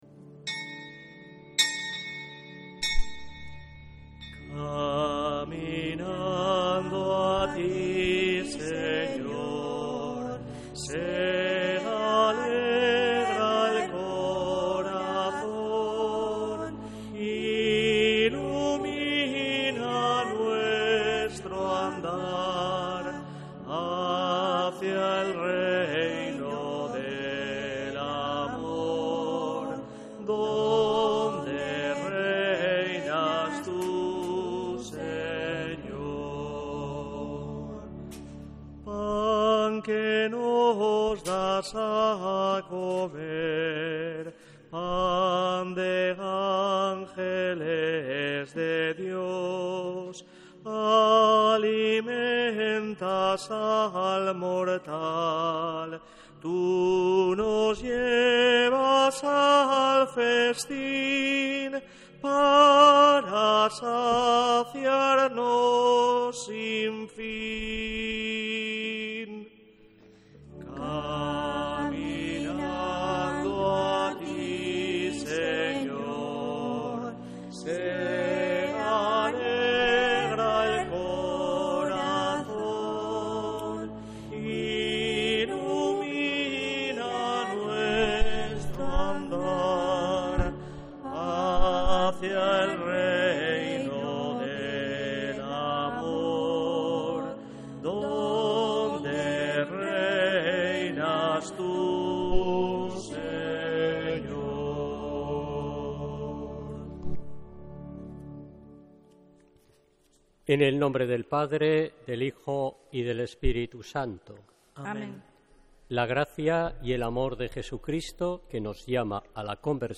Santa Misa desde San Felicísimo en Deusto, domingo 15 de marzo de 2026